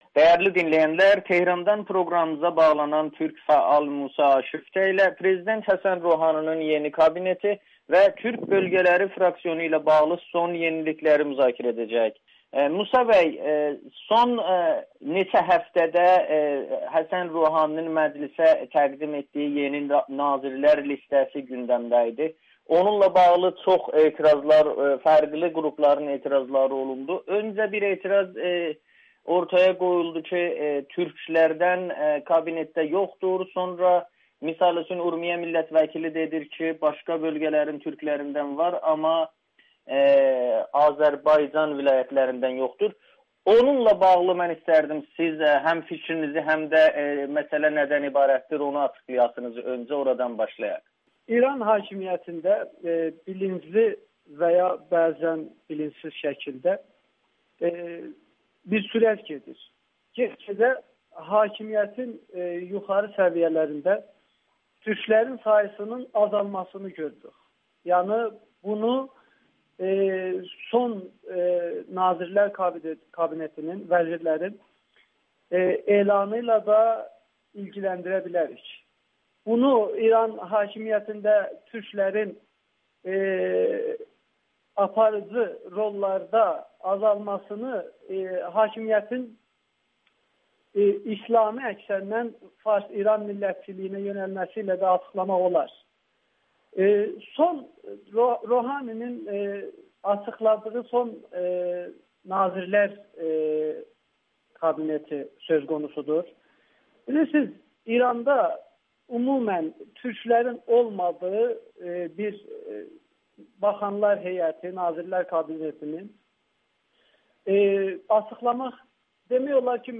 İranın siyasi atmosferində artıq ‘Türk Siyasəti’ndən söz edə bilərik [Audio-Müsahibə]